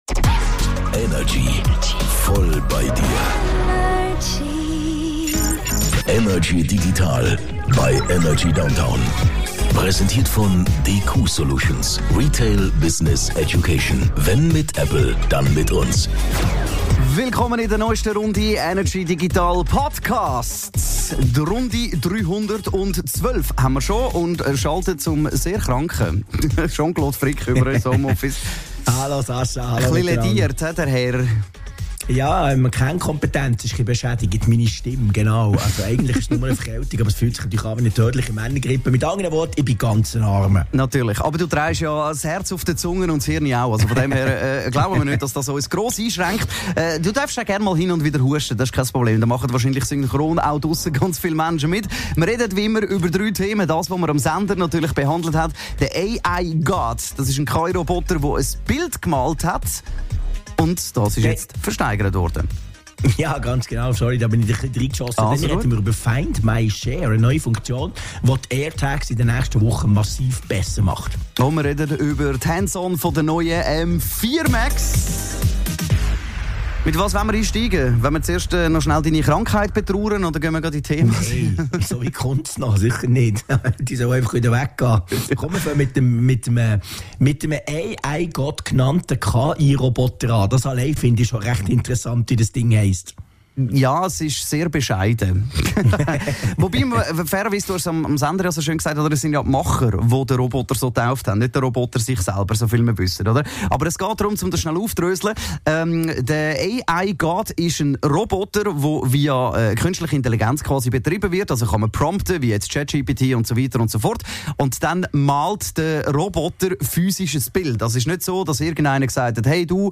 im Energy Studio
aus dem HomeOffice über die digitalen Themen der Woche